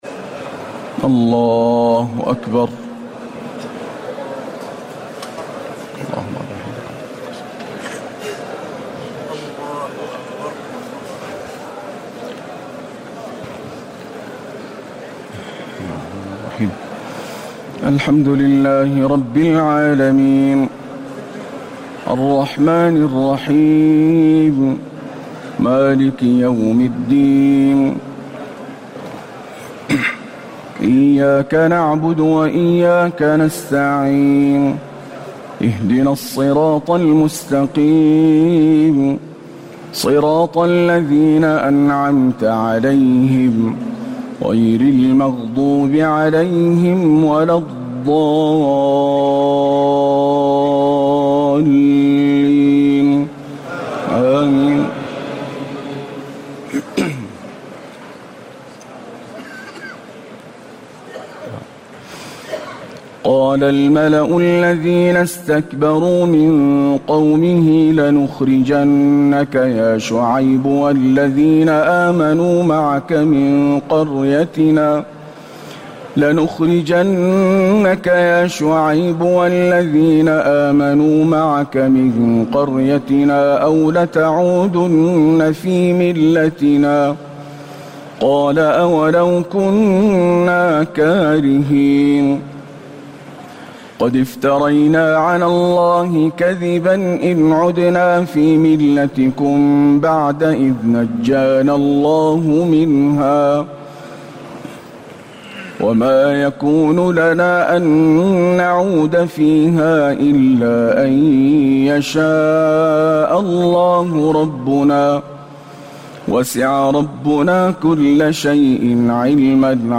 تهجد ليلة 29 رمضان 1439هـ من سورة الأعراف (88-163) Tahajjud 29 st night Ramadan 1439H from Surah Al-A’raf > تراويح الحرم النبوي عام 1439 🕌 > التراويح - تلاوات الحرمين